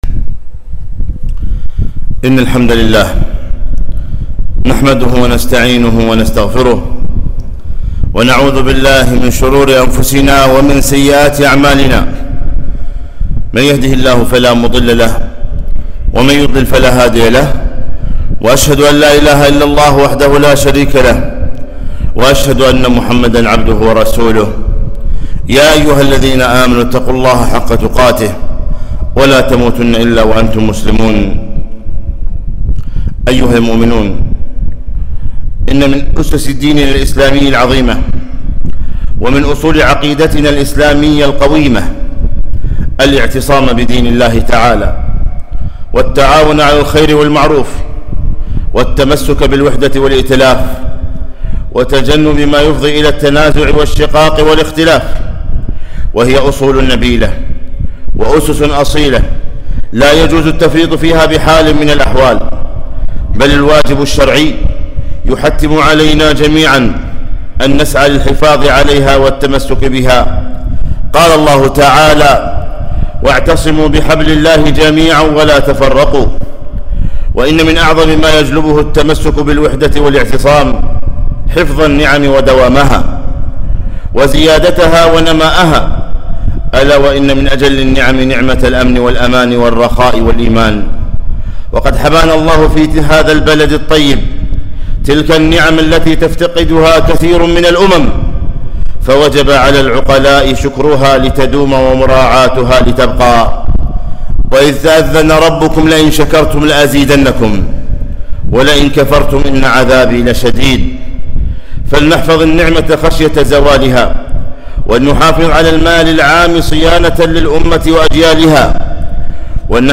خطبة - الكويت أمانة في أعناقنا